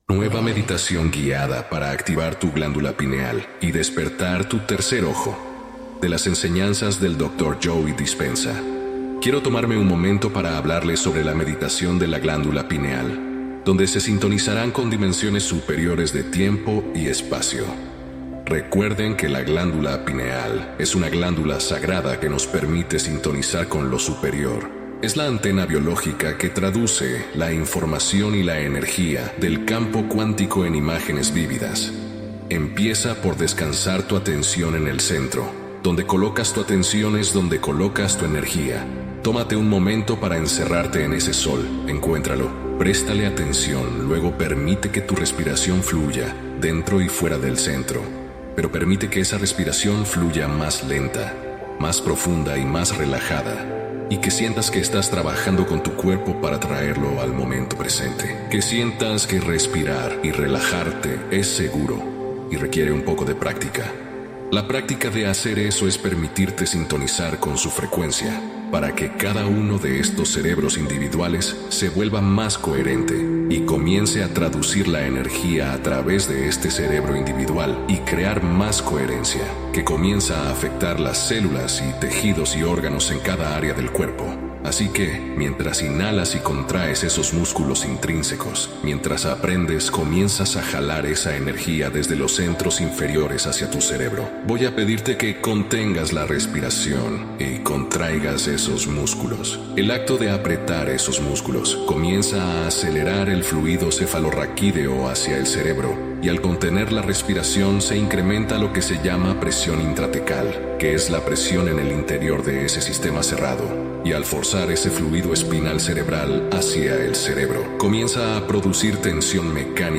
Meditación Guiada Para Un Salto Cuántico | Realidades Paralelas